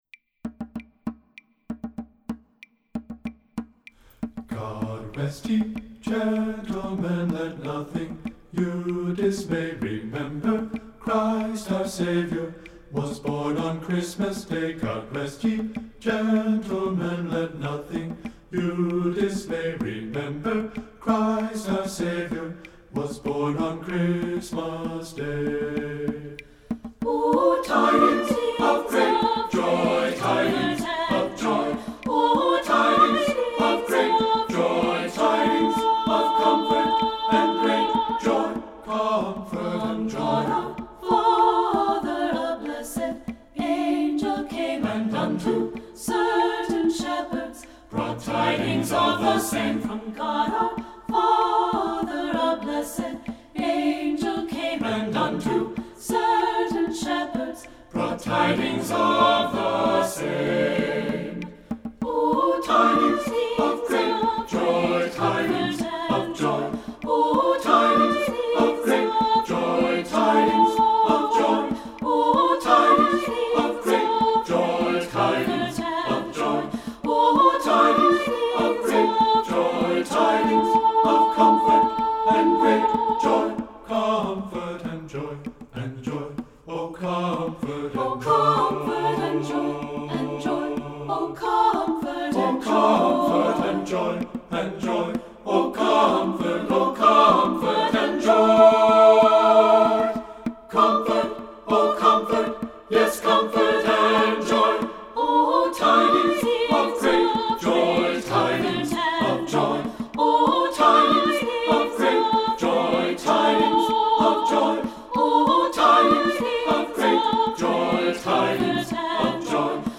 Voicing: SSA a cappella